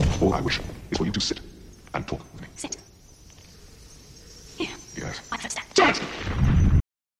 For all experiments, I used a sound clip from one of my favorite movies “Legend” where Tim Curry plays the devil, and Tom Cruise and Mia Sara are the main characters fighting him.
Everything uses a grain size of 20 milliseconds, and a cross fade of 2 milliseconds.
Here is the sound made even shorter (40% of time) so is the same length as the faster/higher version, but has the same pitch as the original again.